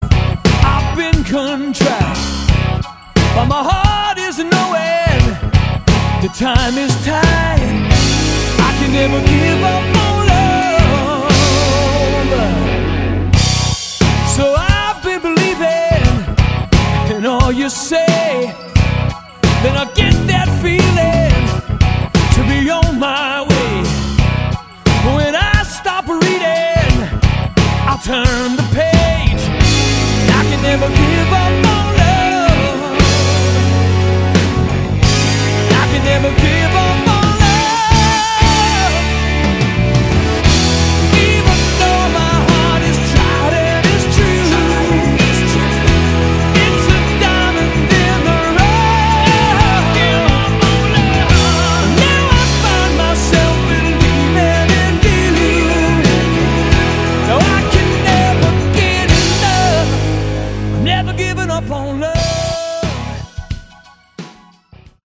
Category: Hard Rock
vocals
guitars
bass
keyboards
drums and percussion